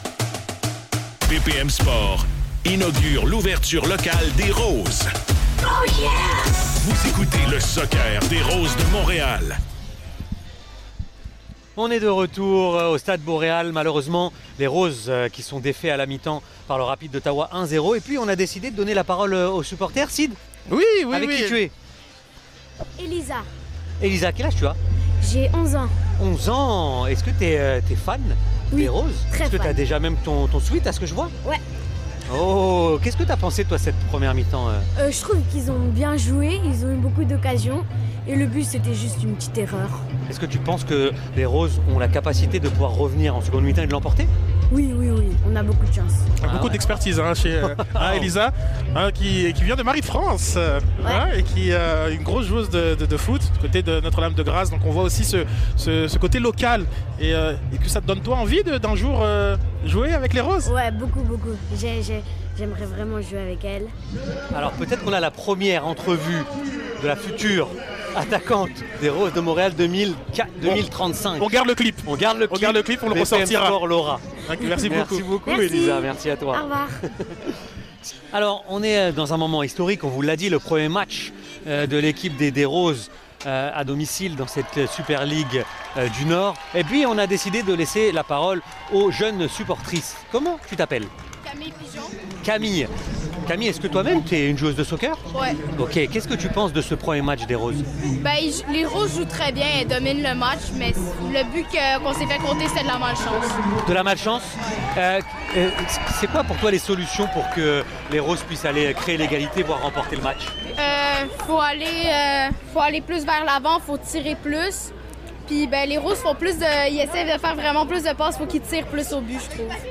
Entretiens avec de jeunes supportrices des Roses de Montréal